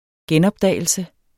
Udtale [ ˈgεnʌbˌdæˀjəlsə ]